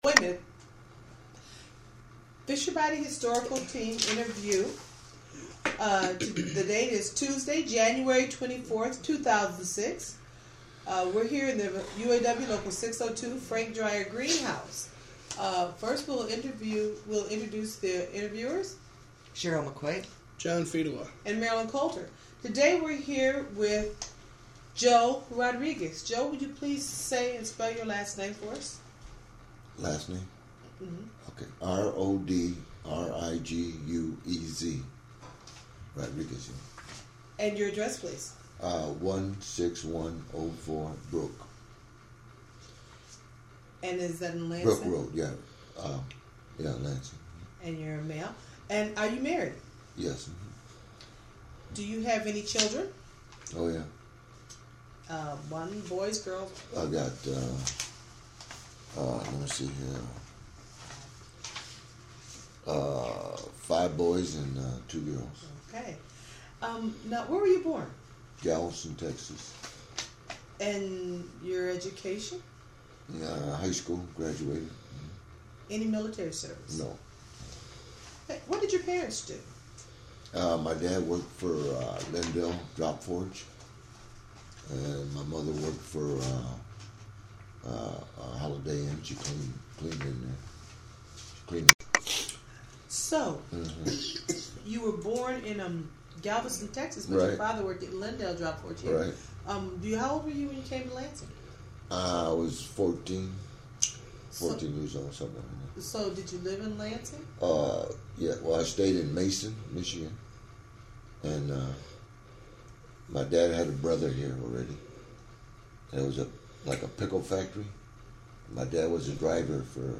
United Auto Workers Local 602/General Motors Oral History Project